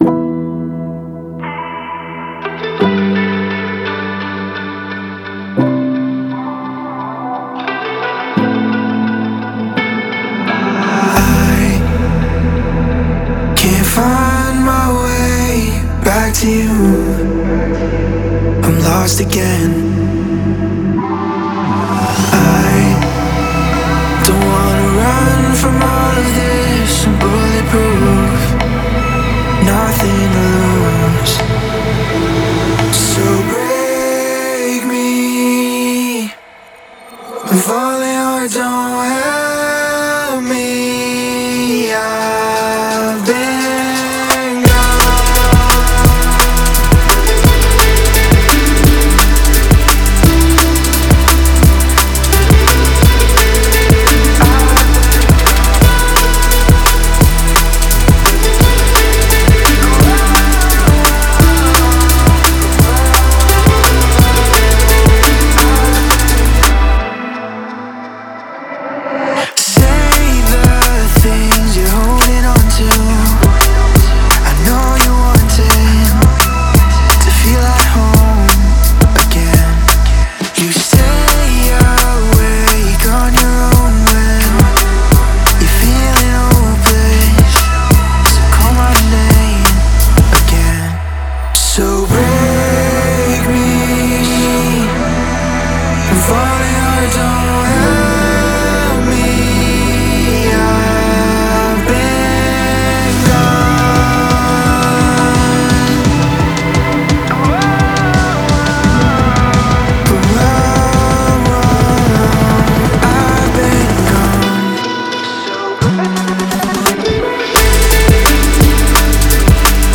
Drum & Bass, Energetic, Romantic, Quirky, Dreamy